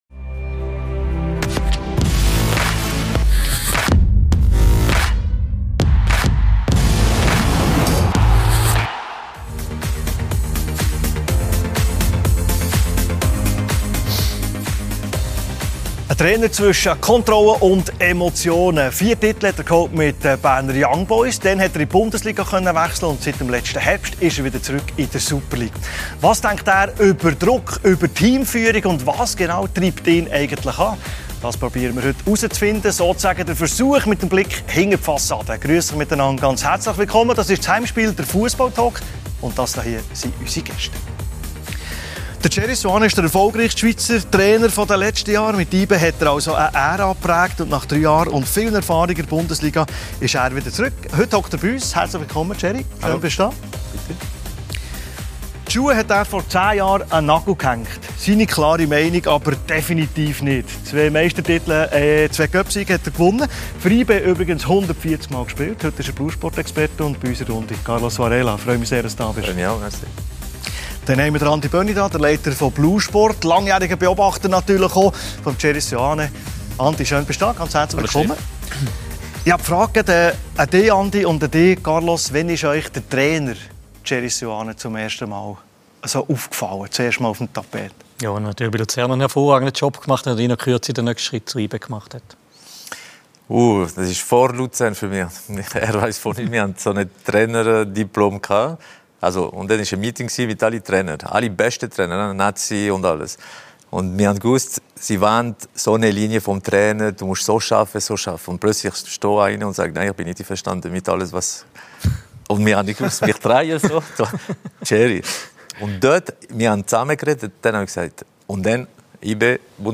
Beschreibung vor 5 Tagen 25 Punkte Rückstand auf Platz 1, Europa-League-Aus, Cup-Blamage – YB steckt in der Krise. Jetzt äussert sich Trainer Gerardo Seoane im Fussball-Talk «Heimspiel».